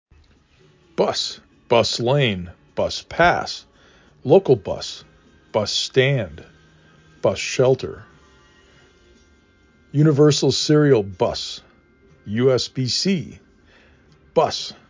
3 Phonemes
b uh s